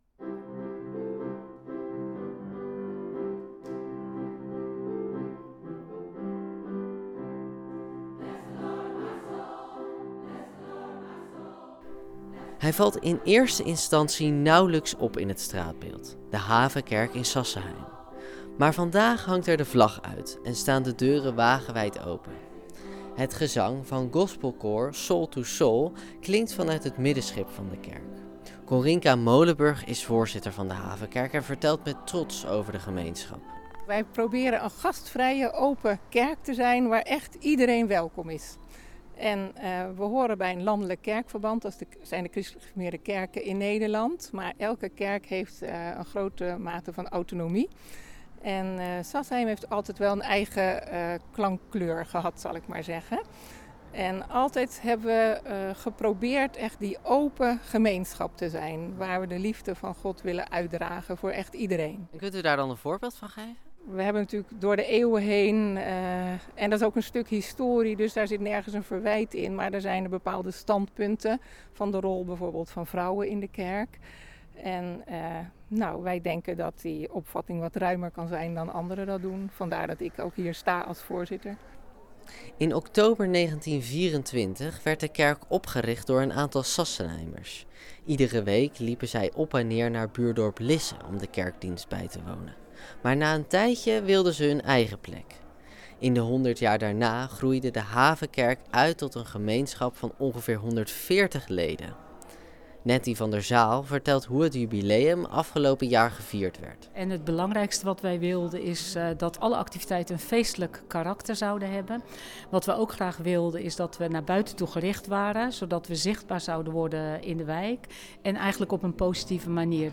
De afsluiting van dit feestelijke jaar vond afgelopen zaterdag plaats, tijdens open monumentendag.
Als je op de stoep voor de ingang van de Havenkerk loopt, is het gezang van gospelkoor Soul2Soul vanuit het middenschip van de kerk al luid en duidelijk te horen. Binnen kijken of klappen bezoekers mee.